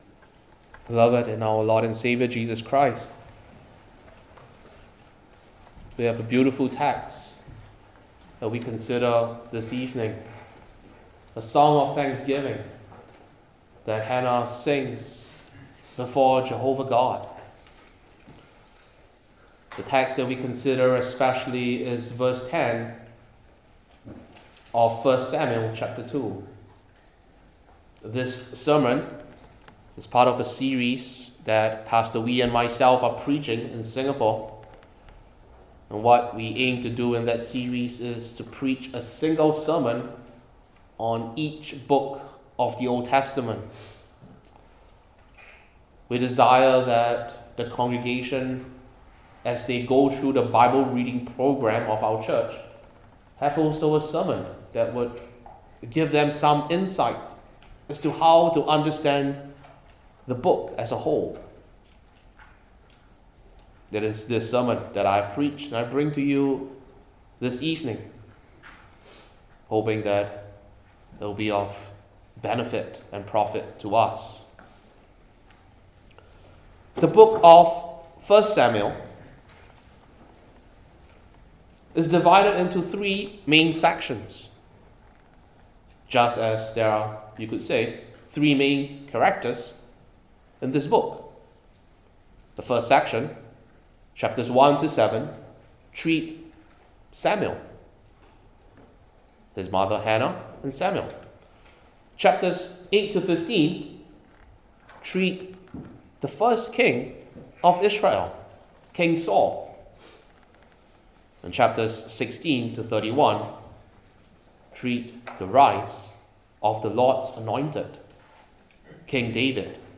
Old Testament Individual Sermons I. The Adversaries of the Anointed II.